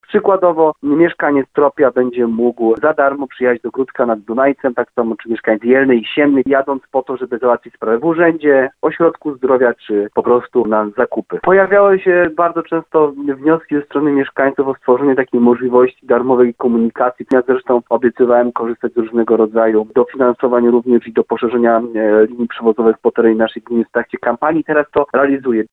Wójt Jarosław Baziak zaznacza, że bezpłatne przewozy będę dotyczyły tylko granic gminy Gródek nad Dunajcem.